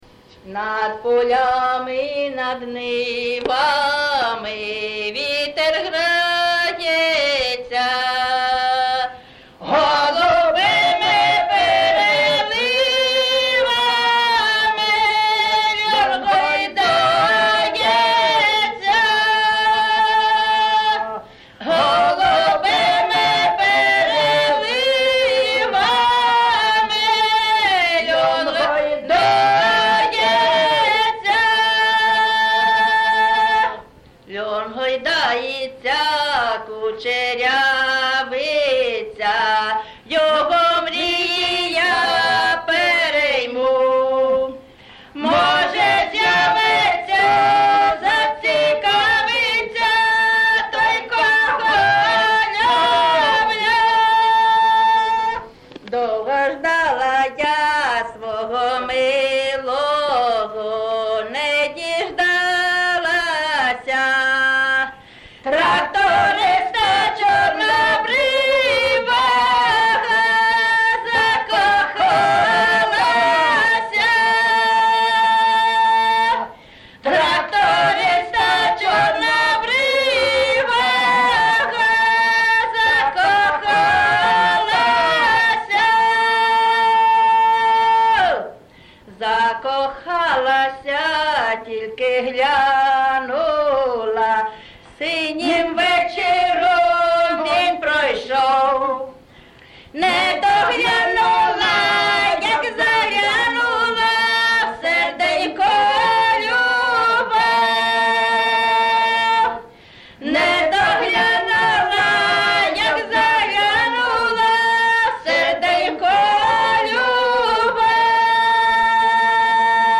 ЖанрПісні з особистого та родинного життя, Сучасні пісні та новотвори
Місце записус. Яблунівка, Костянтинівський (Краматорський) район, Донецька обл., Україна, Слобожанщина